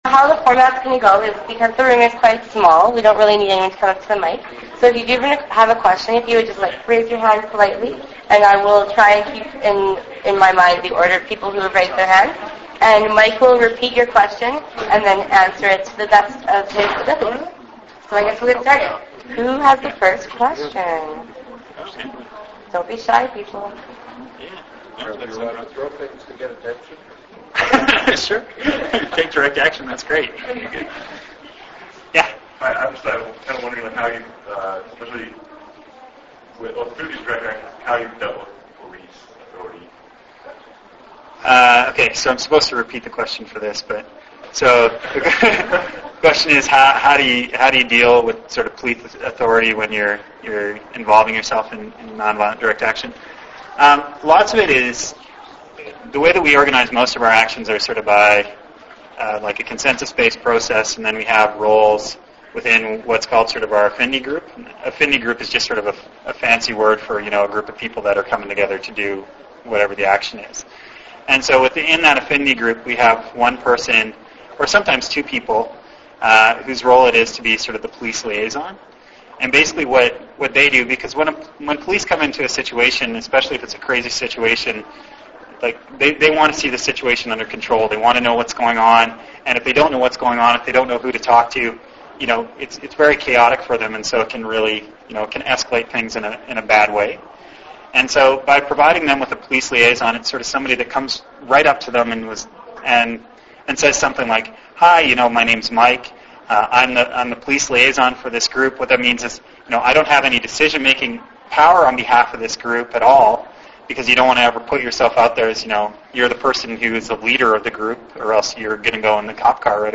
Venue: Galileo’s Gallery, SU building, University of Lethbridge